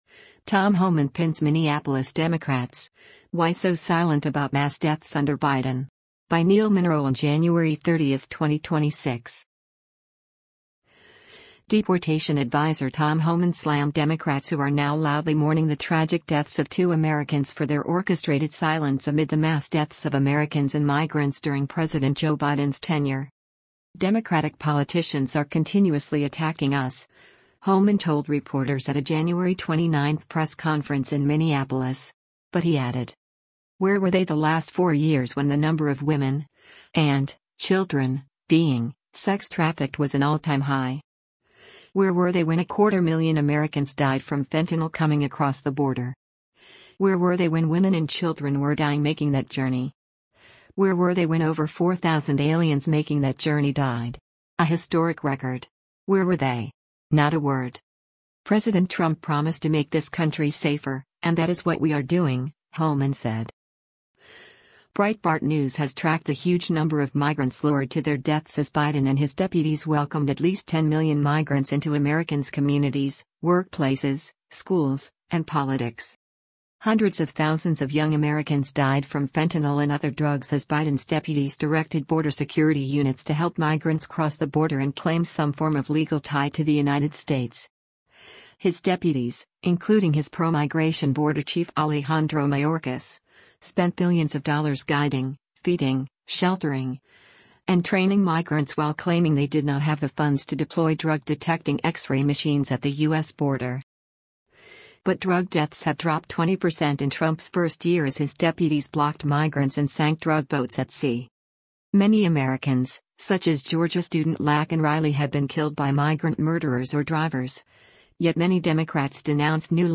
Tom Homan, White House border czar, during a news conference at the Bishop Henry Whipple F
Democratic “politicians are continuously attacking us,” Homan told reporters at a January 29 press conference in Minneapolis.